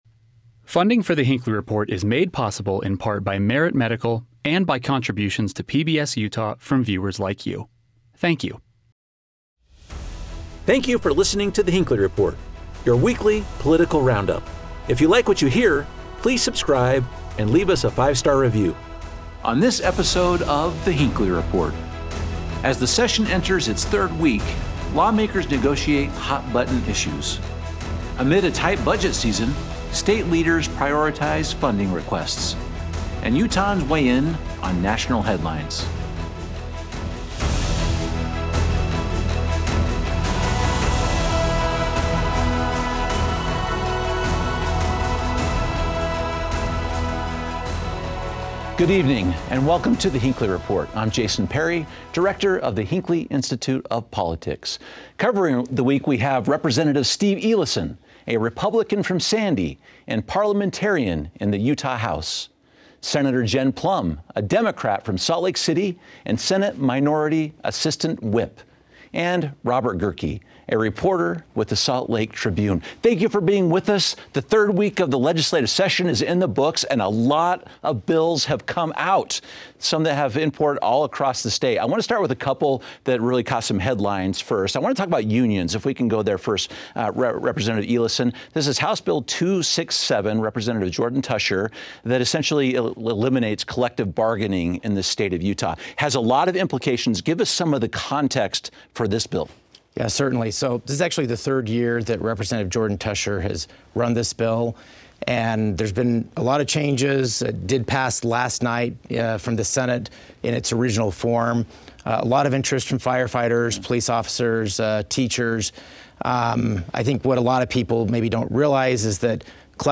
Our expert panel discusses whether we could see that happen, and whether or not union organziers will launch a referendum campaign to let Utah voters decide the bill's fate.